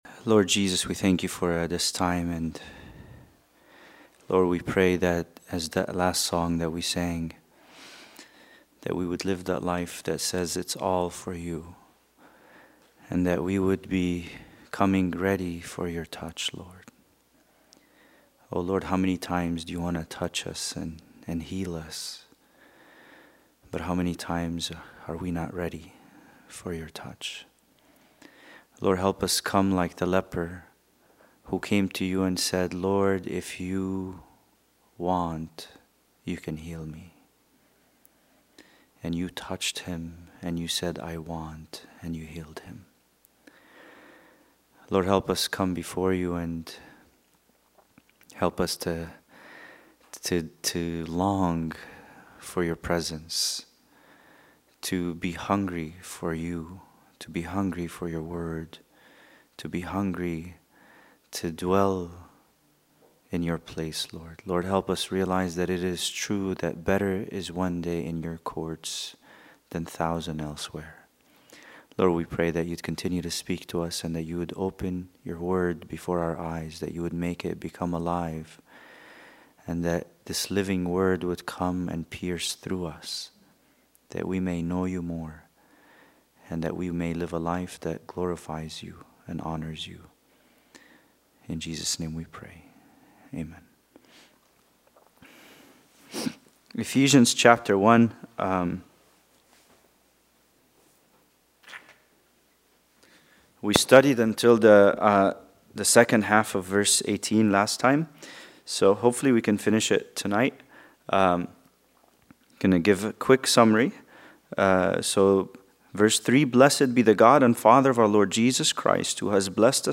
Bible Study: Ephesians 1 part 8